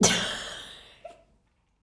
LAUGH 1.wav